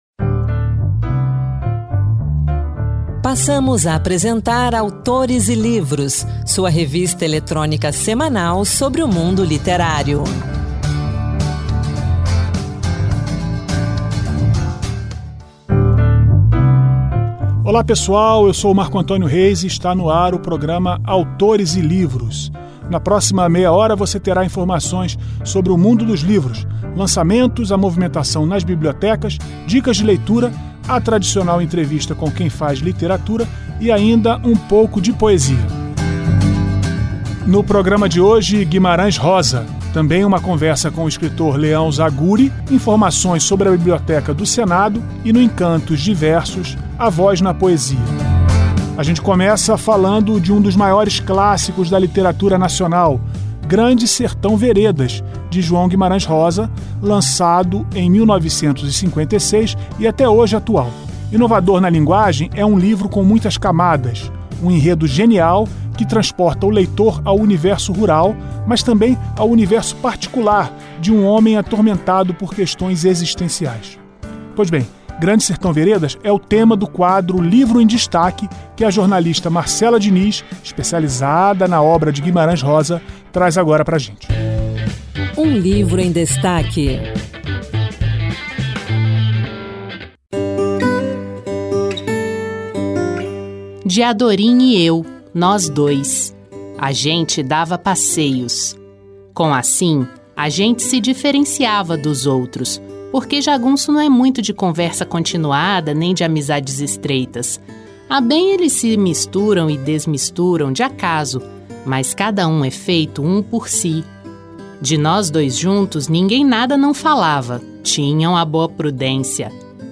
Esta edição do programa Autores e Livros apresenta poesia, um trecho de Guimarães Rosa e uma entrevista com o escritor e médico